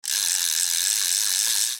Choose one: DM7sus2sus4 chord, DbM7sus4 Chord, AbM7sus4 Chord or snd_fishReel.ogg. snd_fishReel.ogg